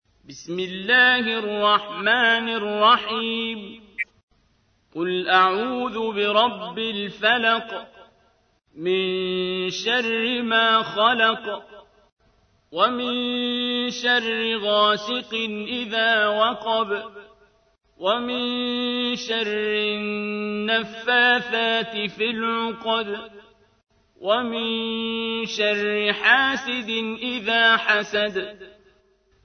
تحميل : 113. سورة الفلق / القارئ عبد الباسط عبد الصمد / القرآن الكريم / موقع يا حسين